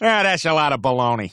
Worms speechbanks
coward.wav